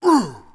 damage_3.wav